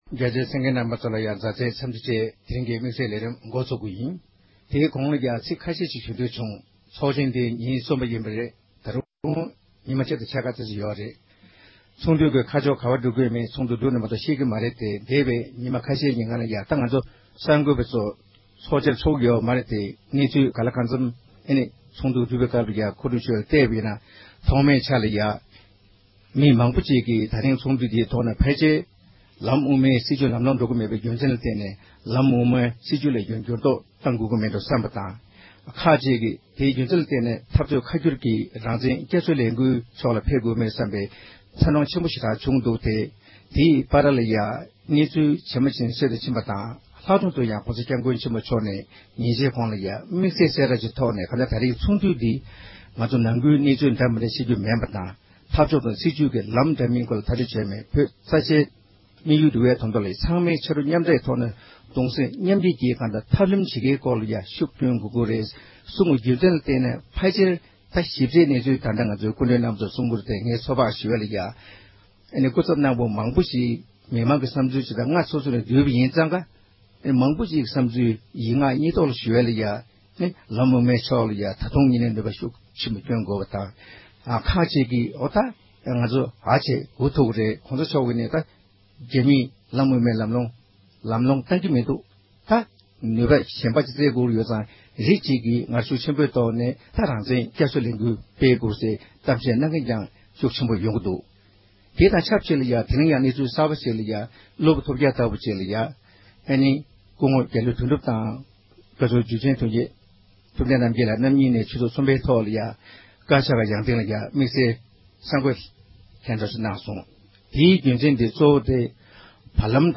ཉིན་གསུམ་པའི་ཚོགས་འདུའི་རྗེས་ཕྱི་རྒྱལ་ཁག་ནས་ཕེབས་པའི་ཚོགས་བཅར་བ་ཁག་ཅིག་གི་ལྷན་ཚོགས་འདུ་འབྲེལ་བའི་གནས་ཚུལ་སྐོར་བགྲོ་གླེང་གནང་བ།